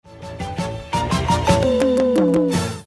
ぴゅーん！シンセドラムの音を作ってみよう。